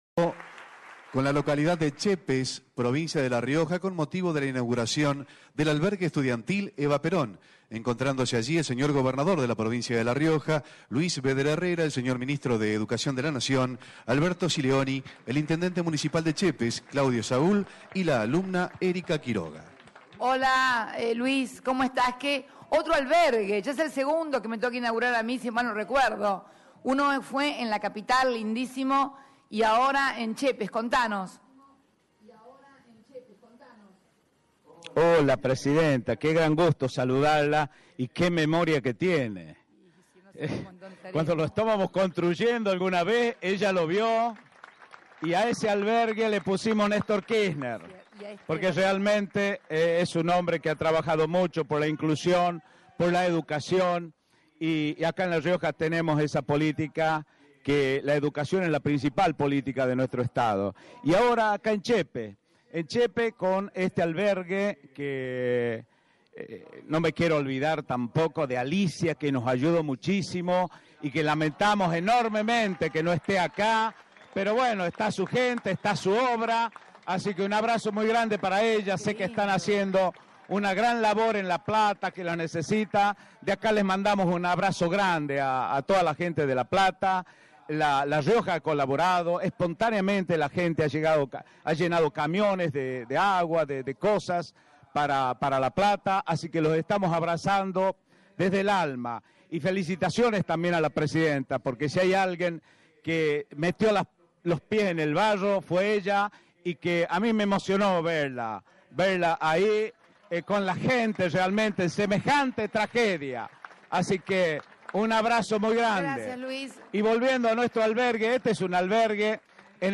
El audio de la videoconferencia de la presidenta Cristina Fernández de Kirchner TV Pública 1
A través de una videoconferencia que fue retransmitida por la Televisión Pública, la presidenta Cristina Fernández de Kirchner inauguró este jueves un albergue estudiantil en la localidad de Chepes, cabecera del departamento Rosario Vera Peñaloza.